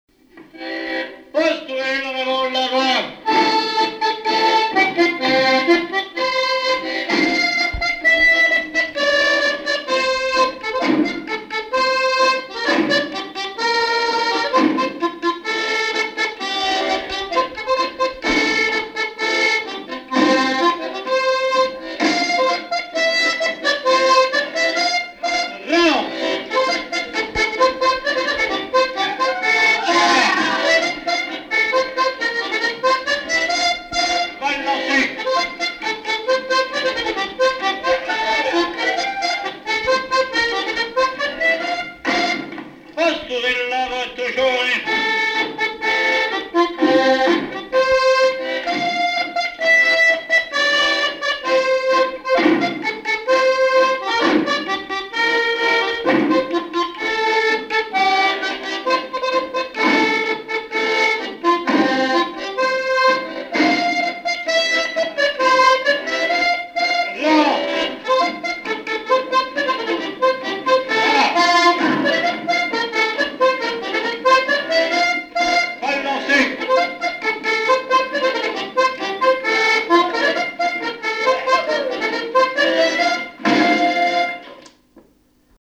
Mémoires et Patrimoines vivants - RaddO est une base de données d'archives iconographiques et sonores.
Quadrille - Pastourelle
danse : quadrille : pastourelle
Pièce musicale inédite